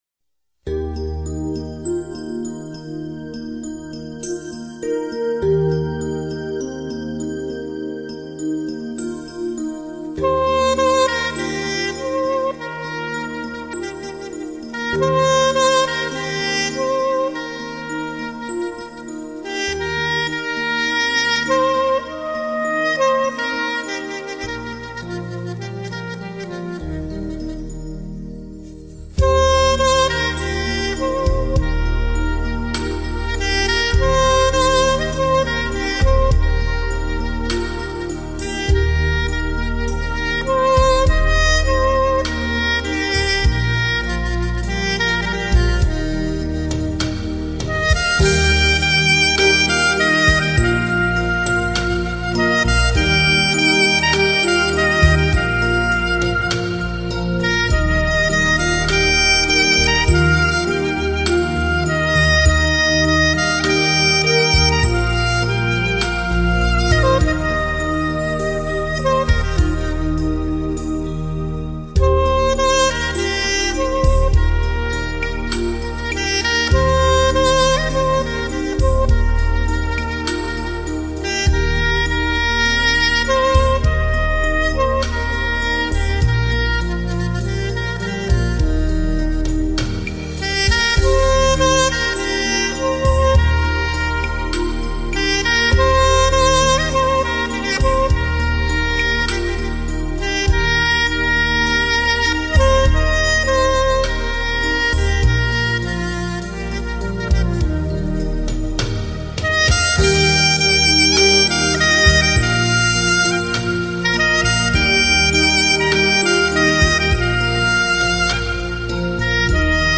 0507-萨克斯名曲茉莉花.mp3